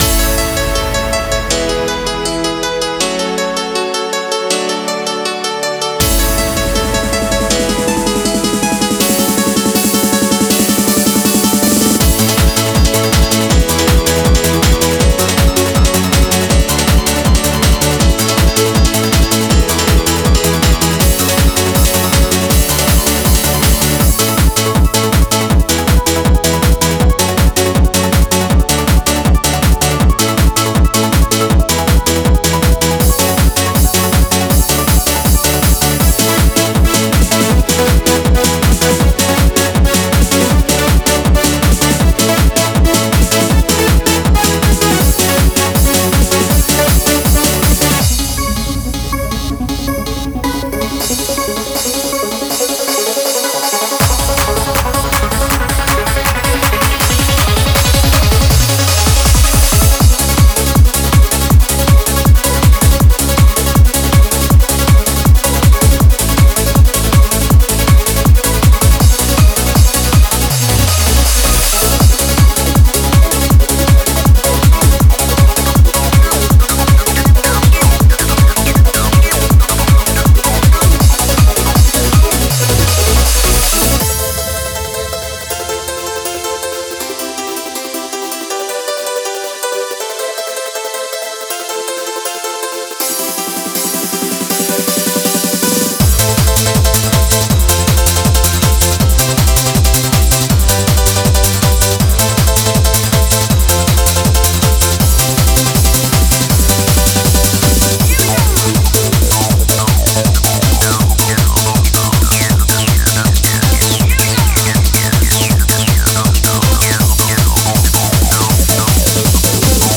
90s Classic Eurodance Hardcore / Hardstyle
Melodies, basslines, synths, pads, vocals & drums
Tempos: 160 BPM